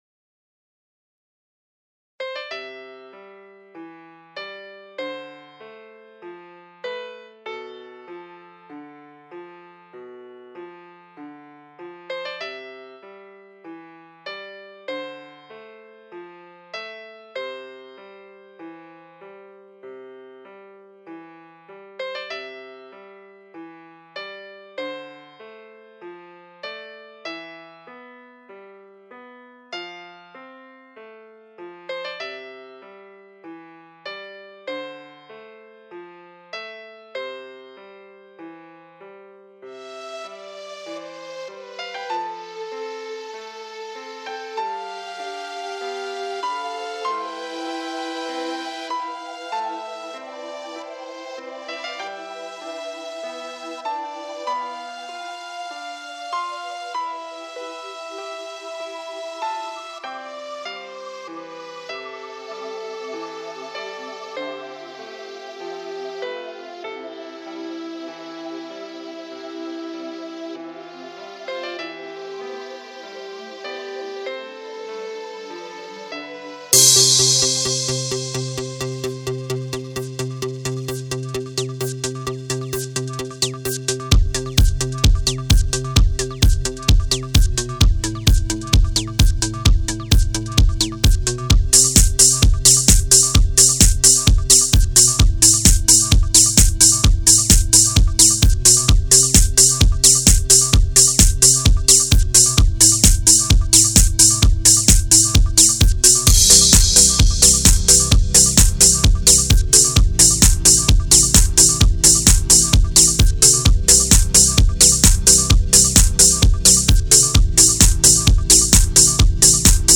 I FINALLY DID IT!!! [House]